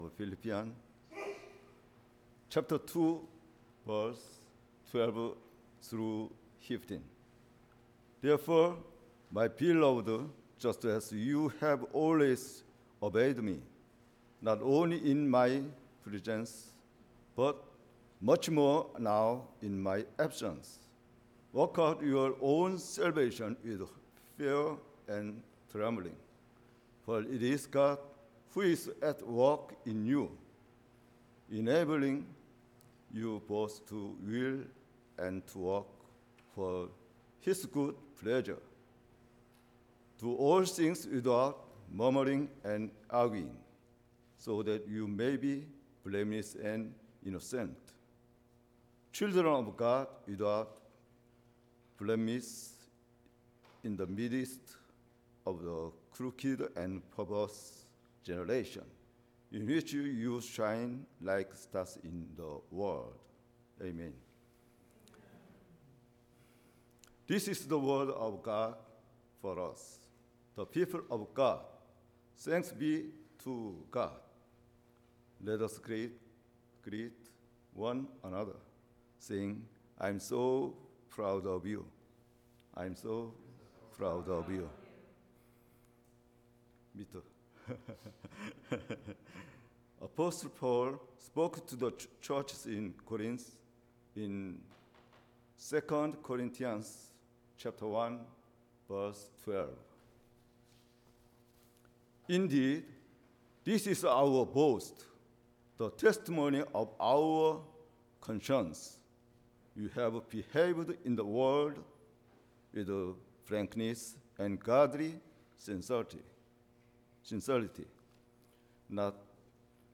WORKING OUT OUR SALVATION – JULY 7 SERMON